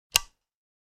light-switch.wav